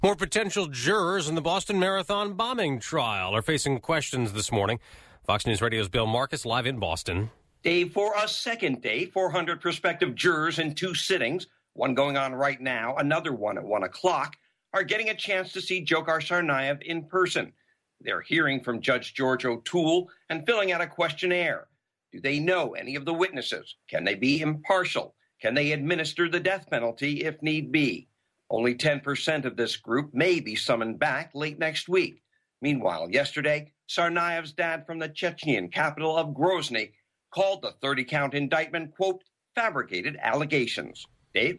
(BOSTON) JAN 6 – LIVE 9AM –